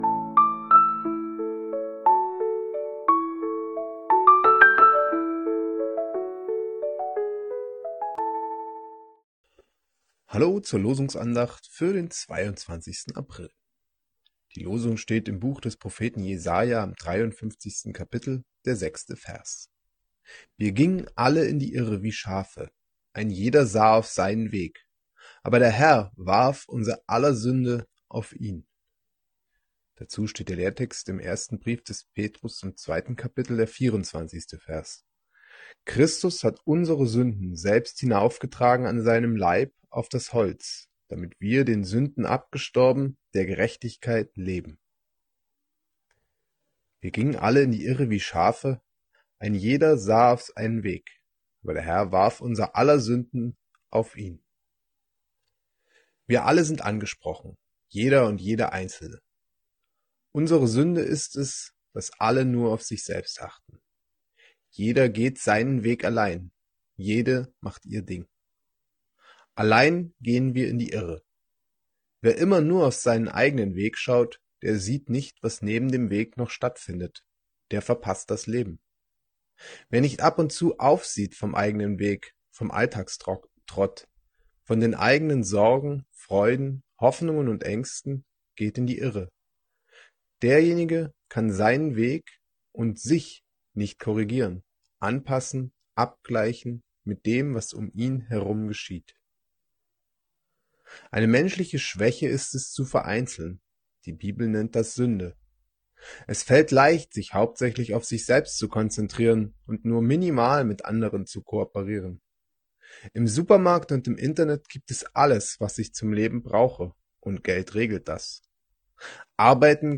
Losungsandacht für Dienstag, 22.04.2025